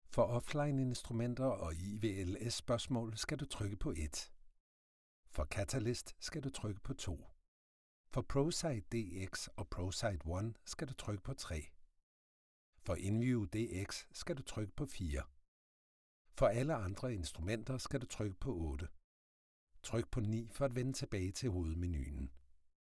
Deep, warm voice.
Phone Greetings / On Hold